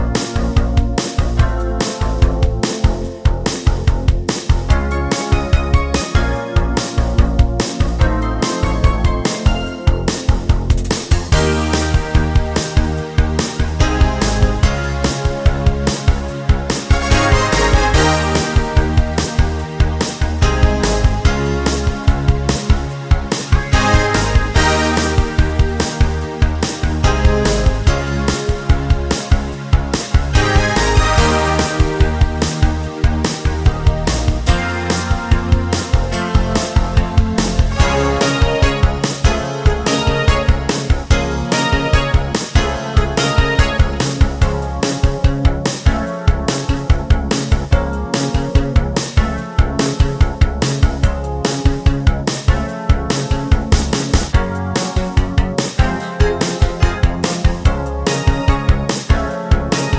no Backing Vocals Pop (1980s) 4:51 Buy £1.50